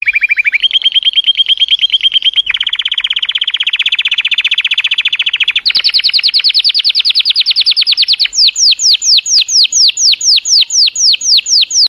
ringtone pajarito 7